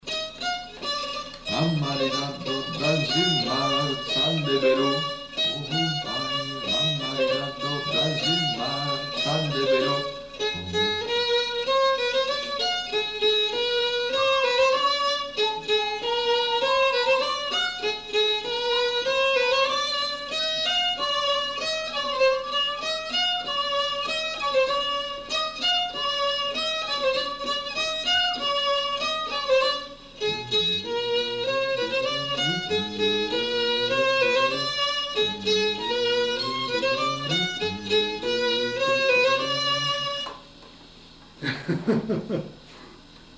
Lieu : Sainte-Livrade-sur-Lot
Genre : morceau instrumental
Instrument de musique : violon
Danse : scottish